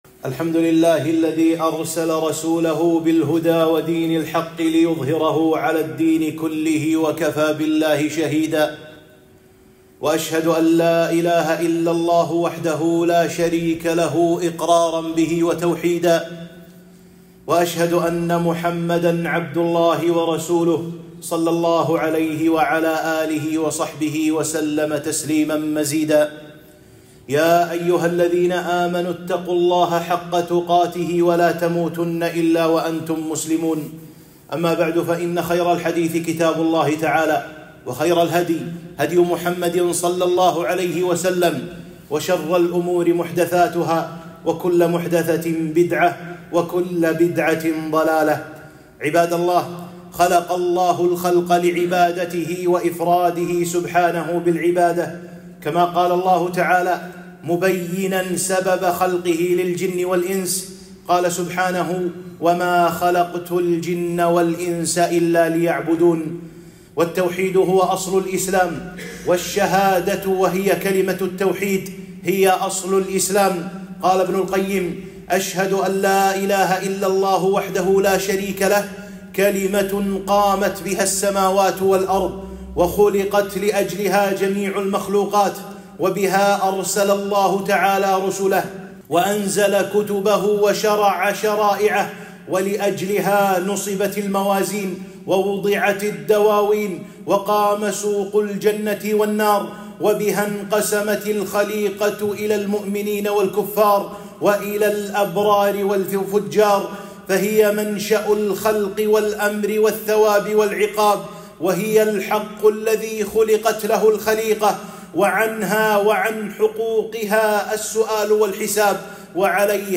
خطبة - أهمية التوحيد وخطورة الشرك، وخطر شرك الألفاظ